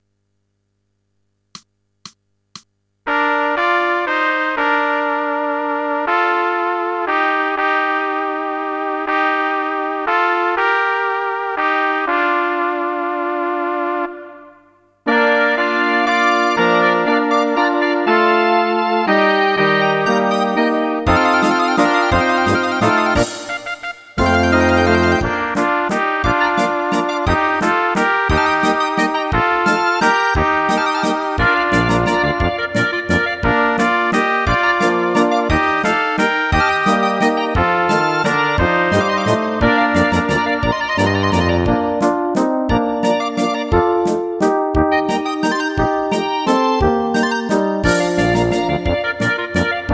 Rubrika: Národní, lidové, dechovka
- valčík
Karaoke